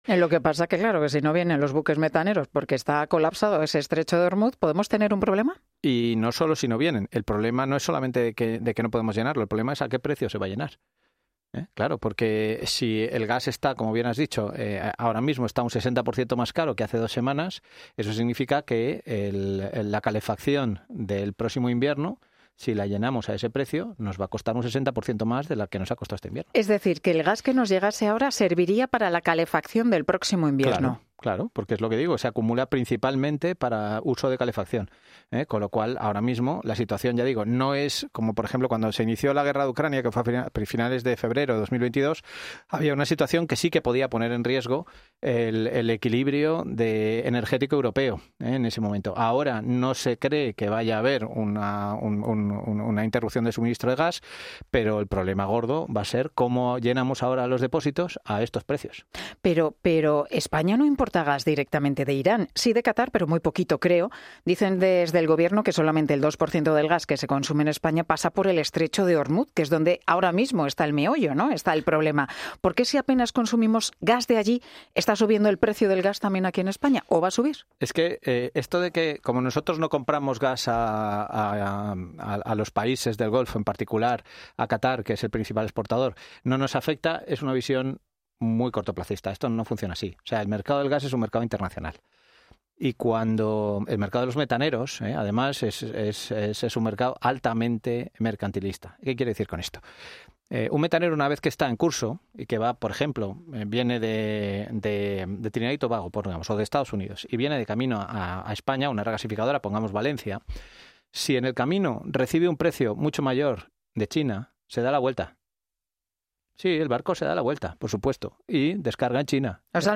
Ingeniero industrial y experto en energía